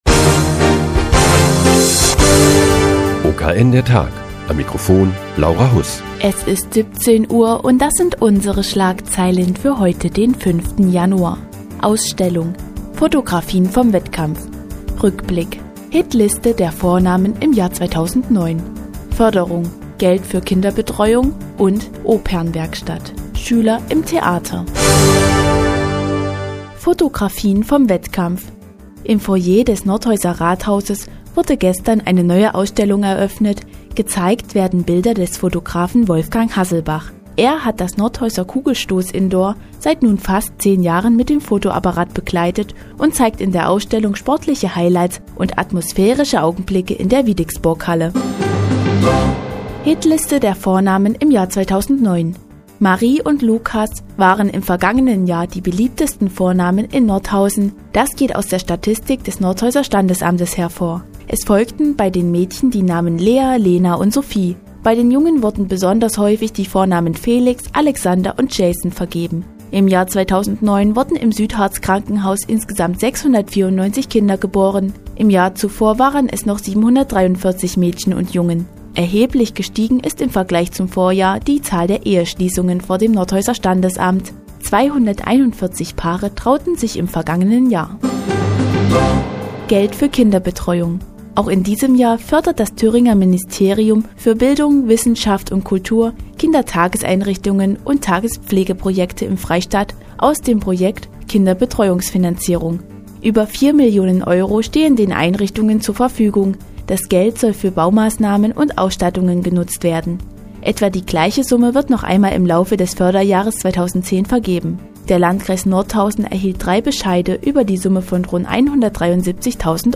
Die tägliche Nachrichtensendung des OKN ist nun auch in der nnz zu hören. Heute geht es um die Ausstellung im Foyer des Nordhäuser Rathauses und die Hitliste der Vornamen im Jahr 2009.